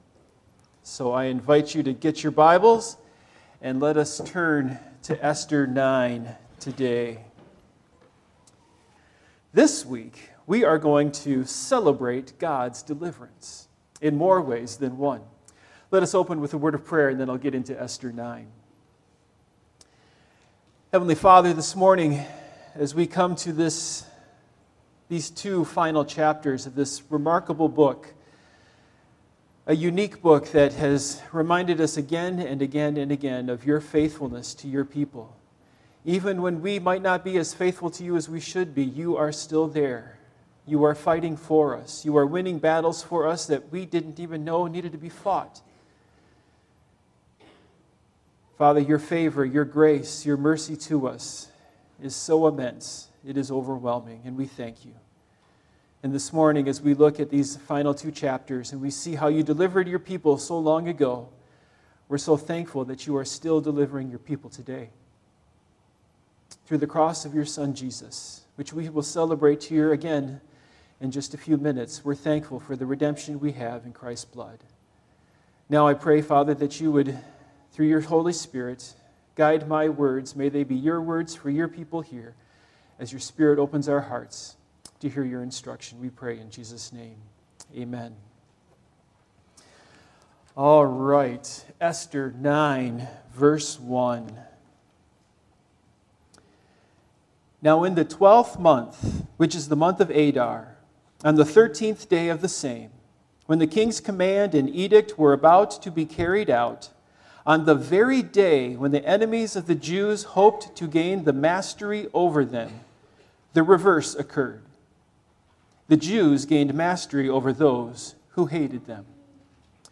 Esther Passage: Esther 9:1 - 10:3 Service Type: Morning Worship Topics